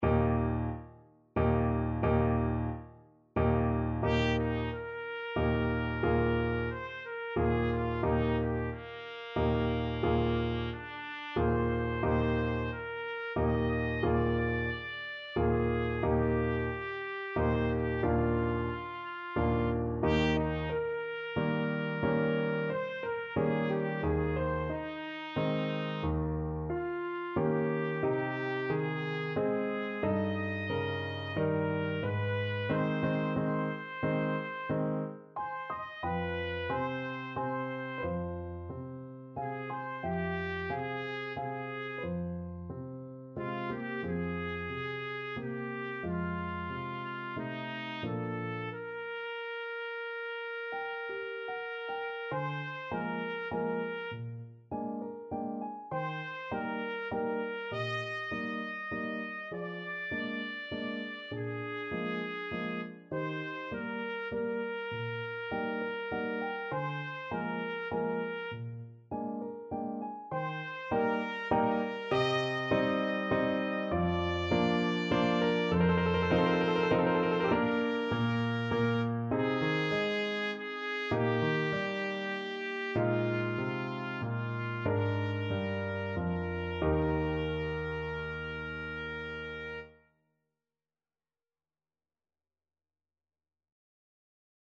Trumpet
Eb major (Sounding Pitch) F major (Trumpet in Bb) (View more Eb major Music for Trumpet )
3/4 (View more 3/4 Music)
~ = 90 Allegretto moderato
Classical (View more Classical Trumpet Music)